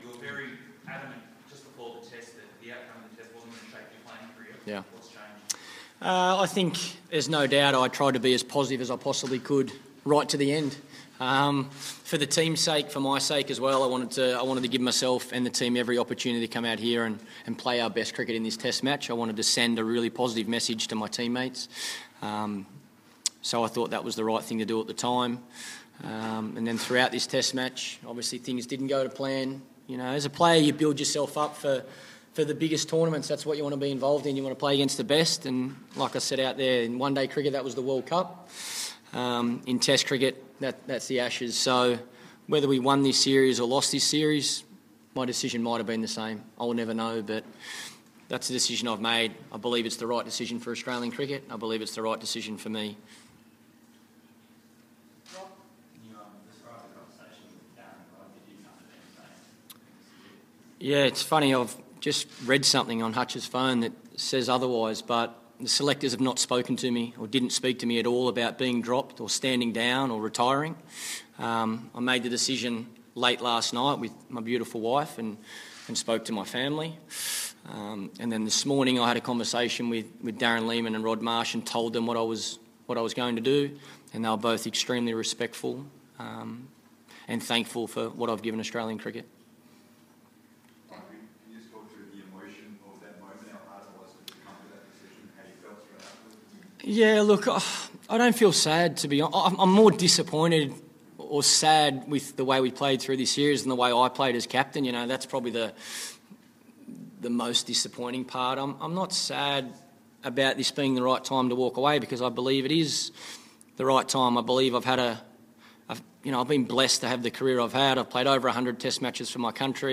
Michael Clarke - Trent Bridge Press Conference